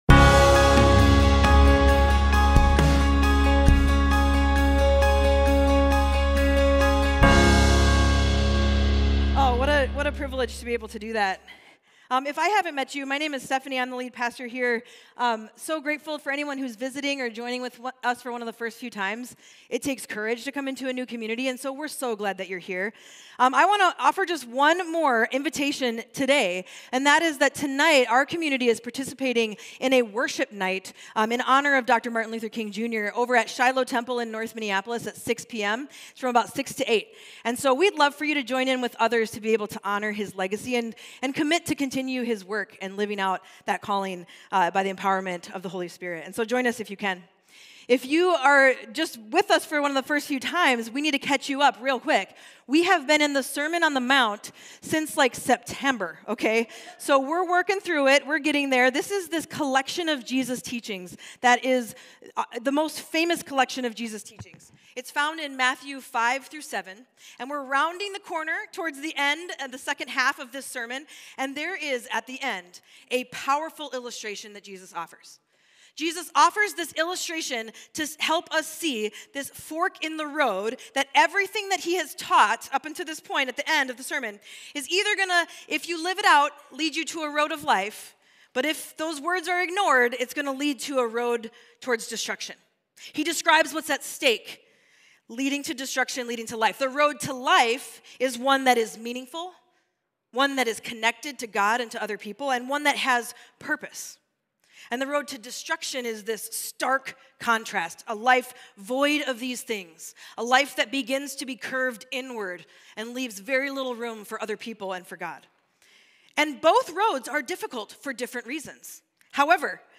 Mill City Church Sermons The Road to Life: Wealth and God Jan 21 2025 | 00:35:46 1x 00:00 / 00:35:46 Subscribe Share RSS Feed Share Link Embed